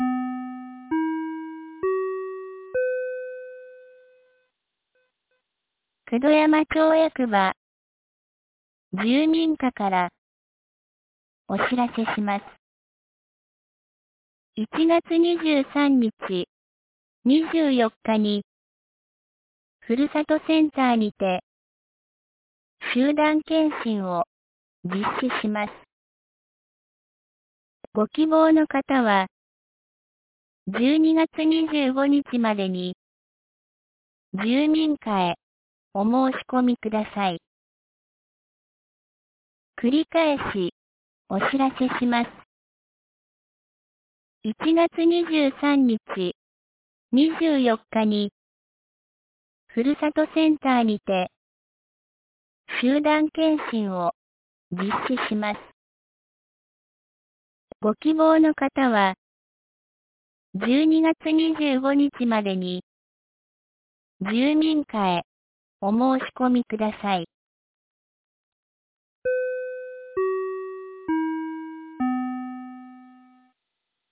2024年12月20日 10時31分に、九度山町より全地区へ放送がありました。